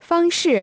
方式 fāngshì 方式、方法、様式